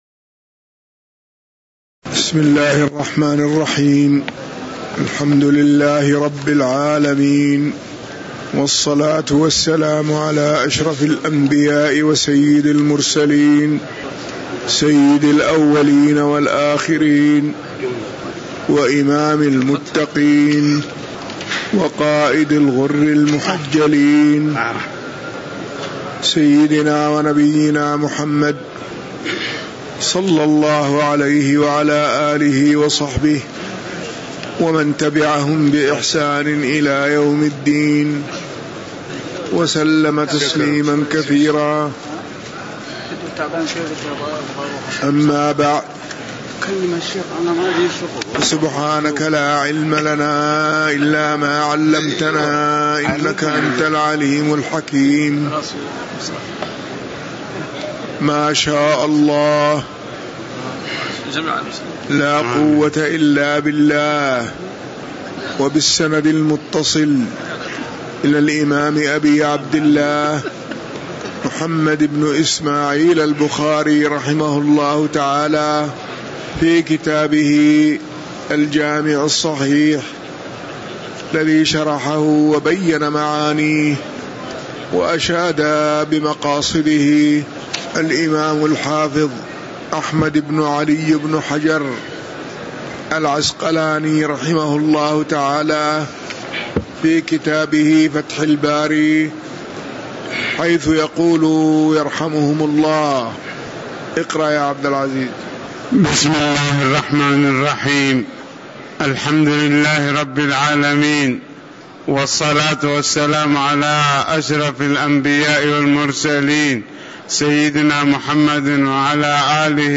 تاريخ النشر ١٤ ذو القعدة ١٤٤٠ هـ المكان: المسجد النبوي الشيخ